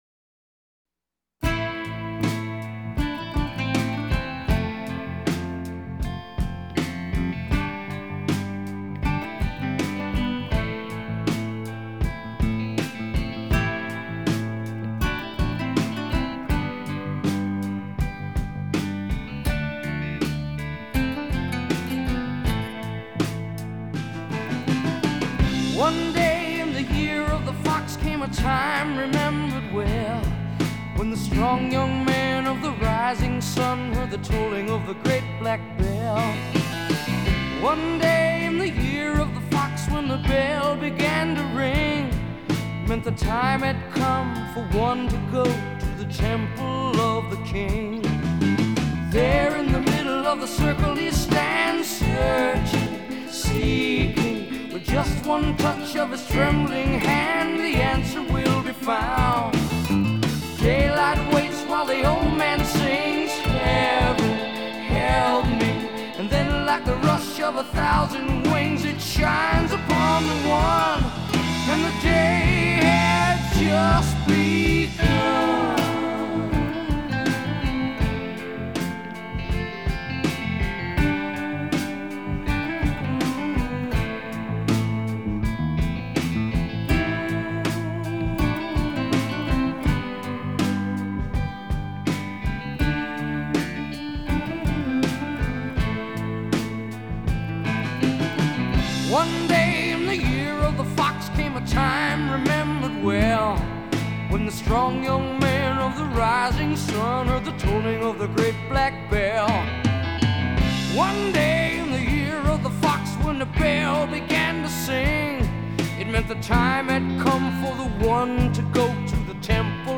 Жанр: Хард-рок